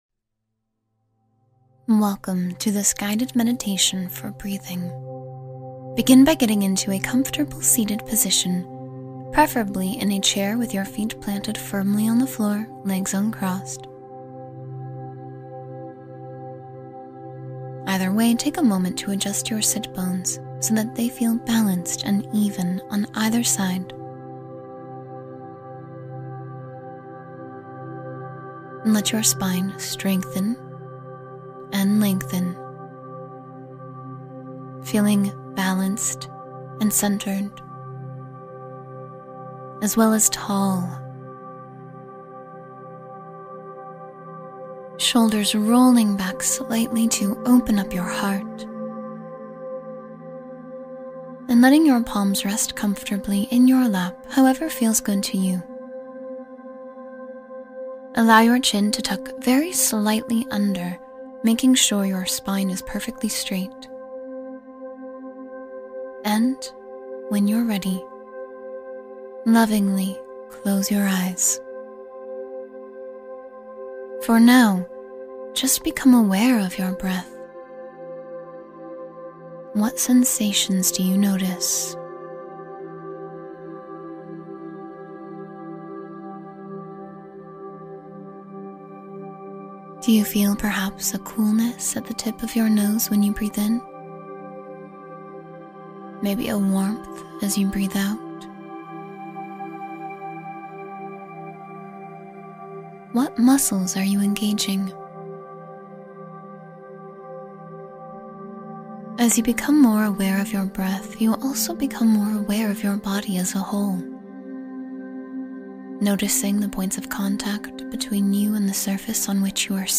Guided Breathing Meditation for Deep Calm and Relaxation